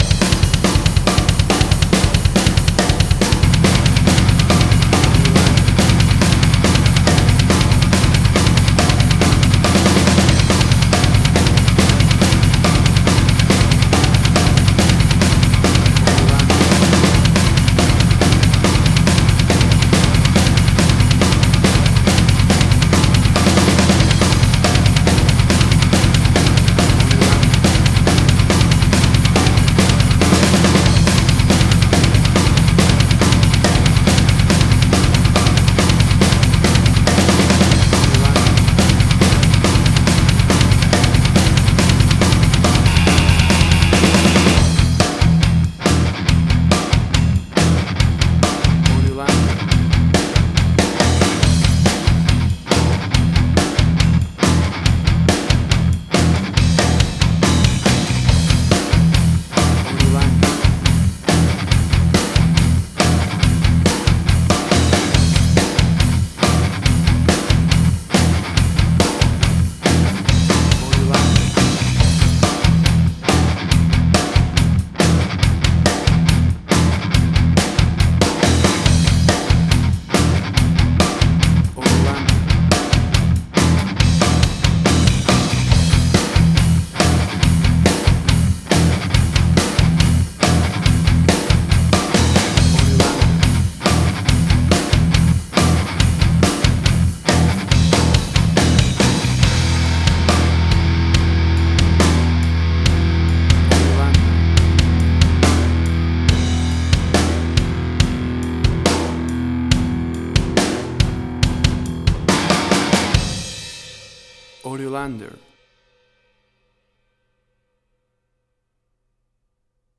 Hard Rock, Similar Black Sabbath, AC-DC, Heavy Metal.
Tempo (BPM): 140